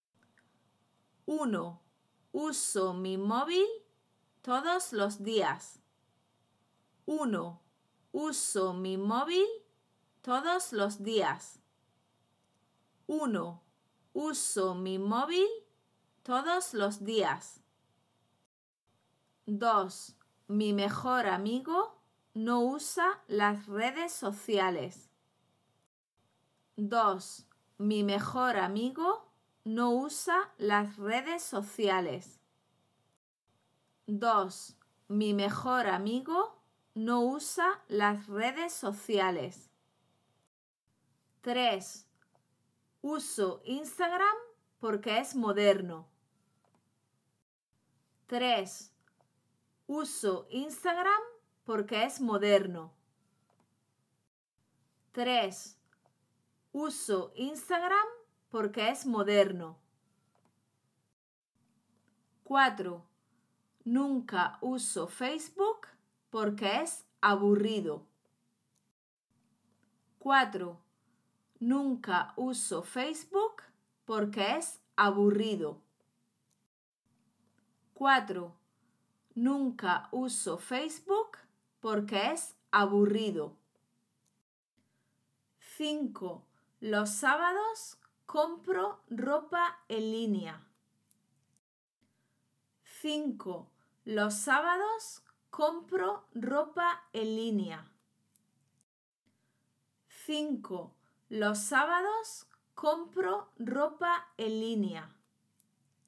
– Listening dictation task